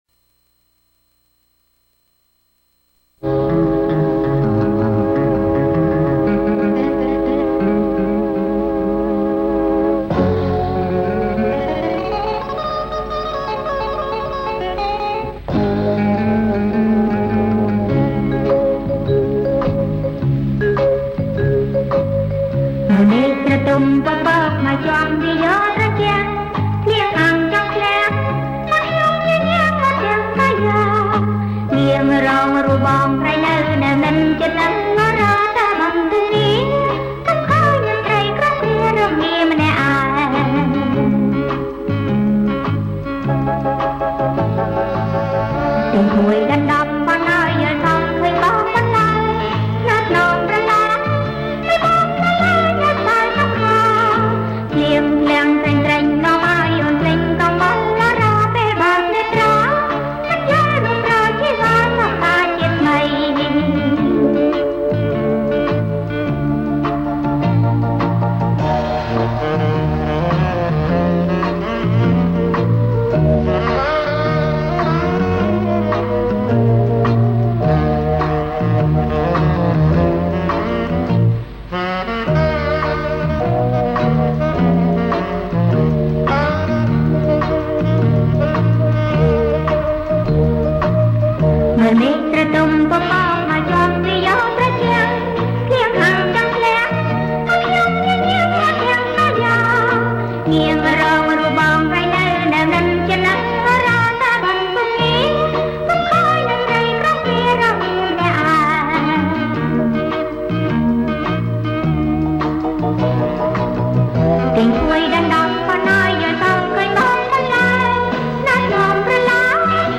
• ប្រគំជាចង្វាក់